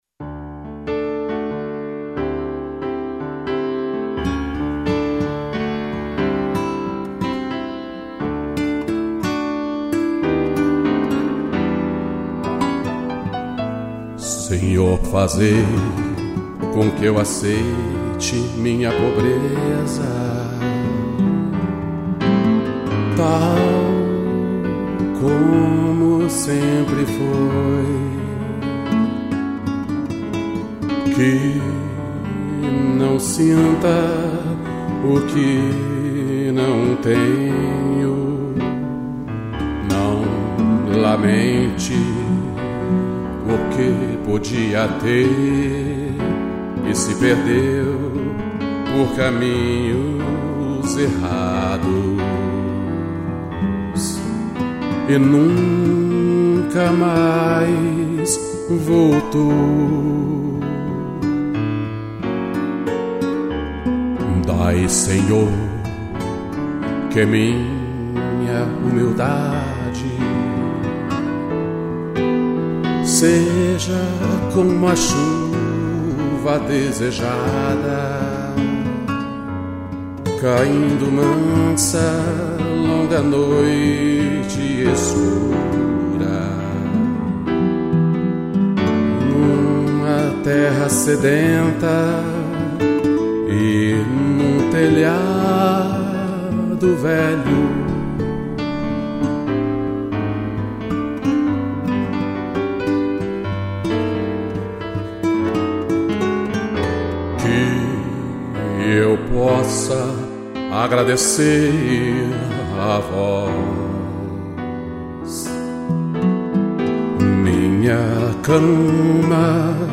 voz e violão